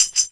D2 SHAK-73.wav